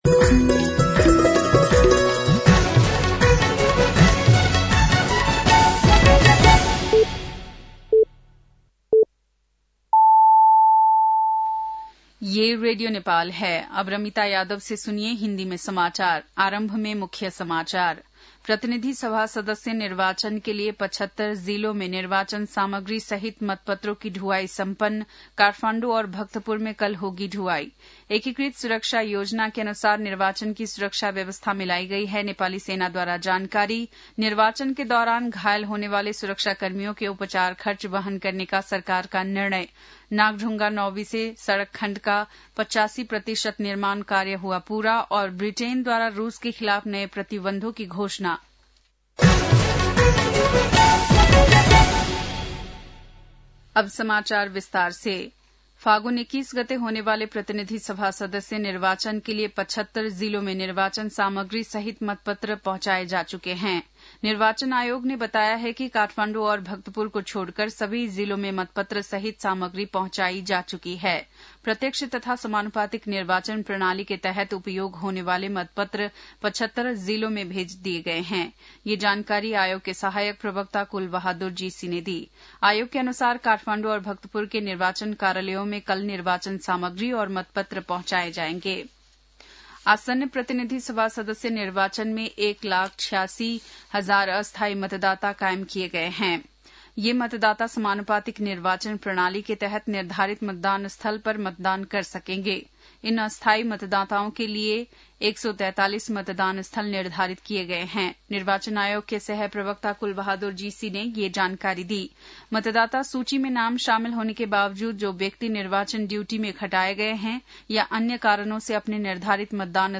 बेलुकी १० बजेको हिन्दी समाचार : १३ फागुन , २०८२